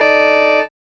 5005L CARHRN.wav